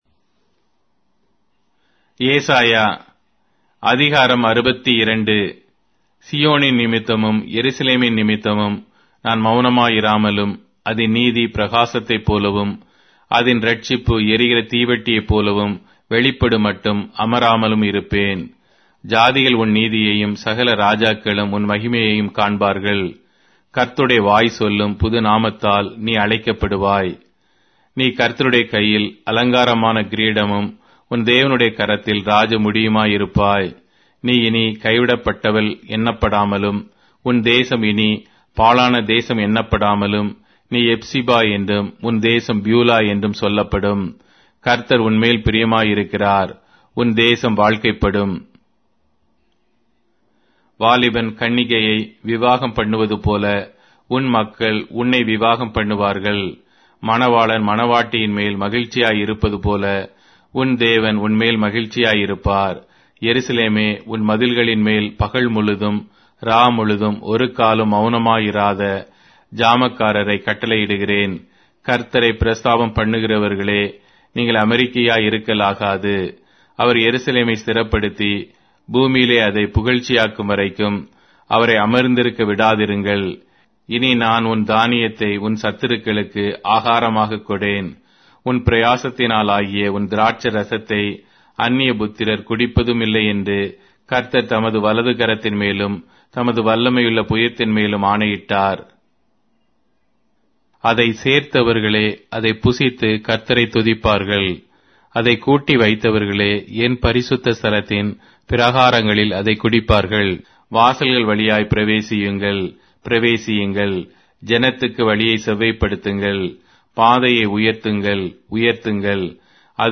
Tamil Audio Bible - Isaiah 45 in Urv bible version